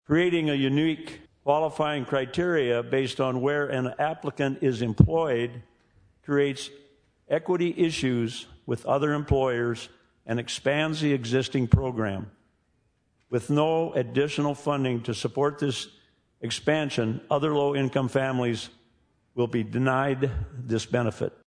Kolbeck said this bill will create equity issues.